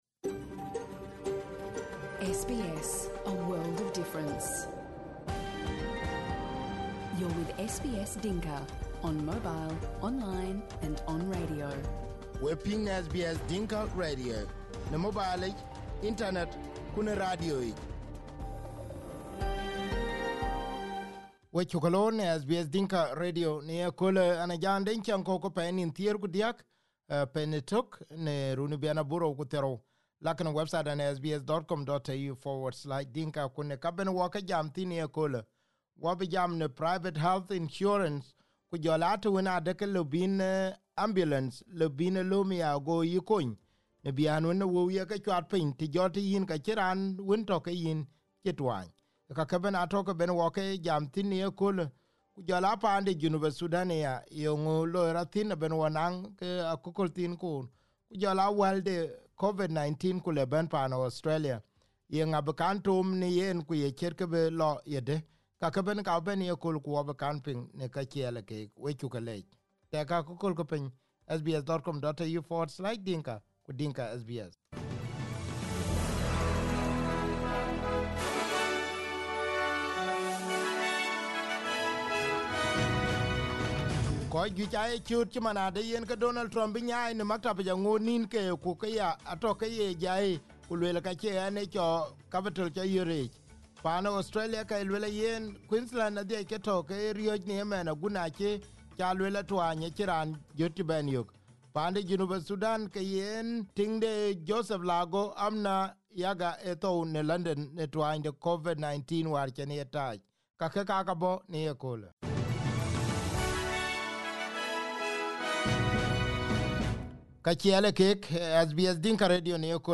SBS Dinka News